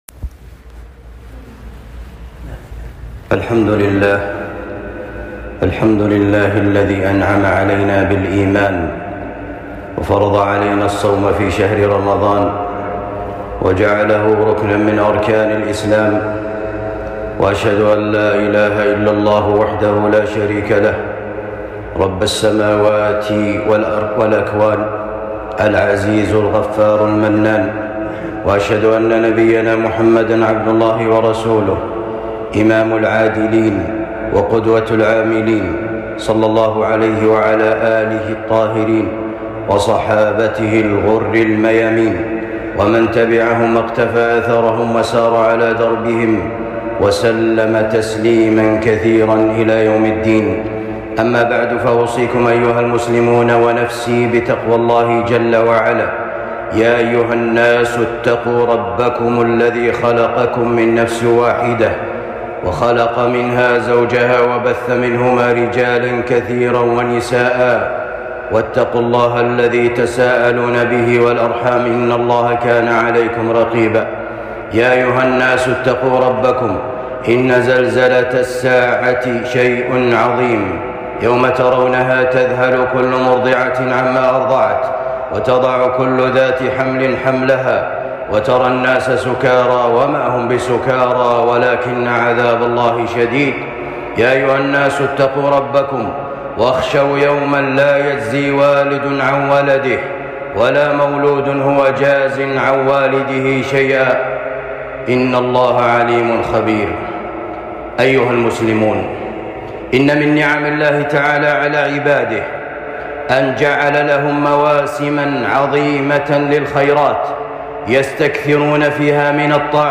خطبة بعنوان كيف نستقبل شهر رمضان